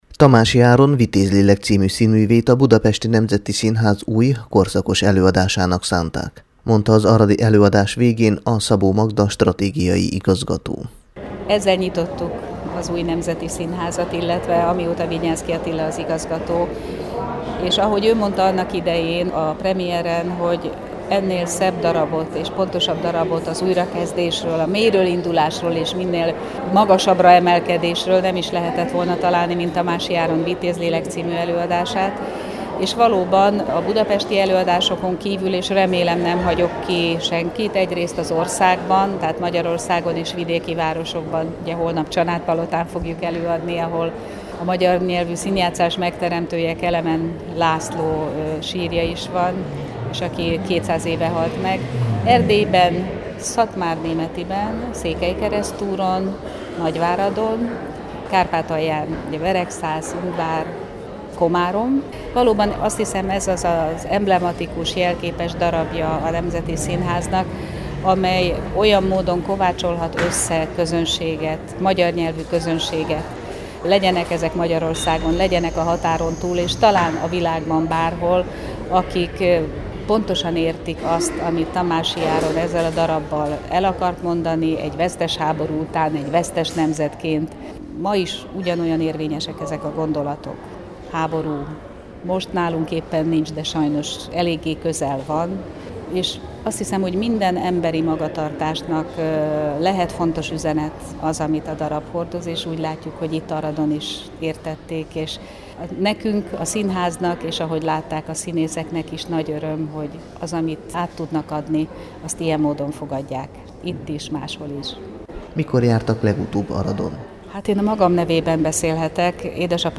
vitez_lelek_nemzeti_szinhaz_aradon.mp3